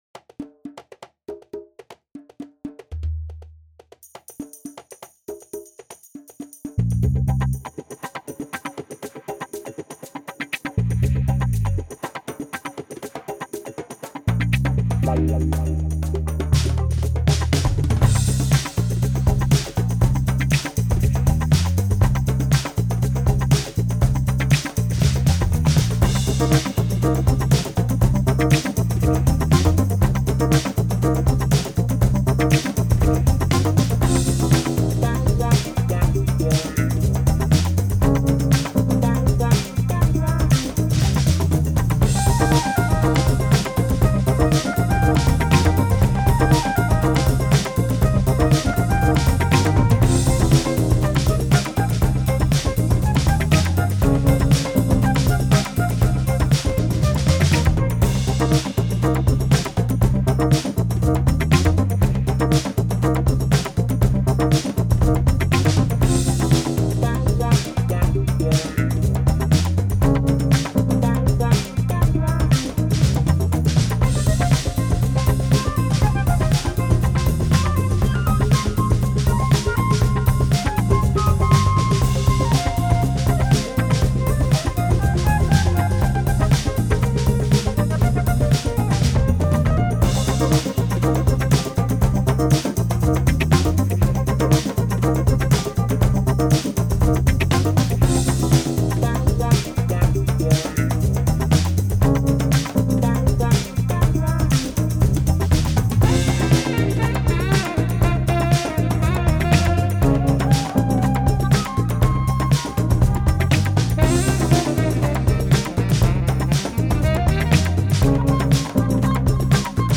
I've been in a bit of creative slump lately, so I decided to go nuts with Apple Loops and just see what would happen. I put together something pretty funky and thought I'd share it.